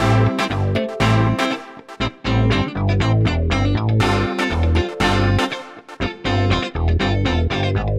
29 Backing PT3.wav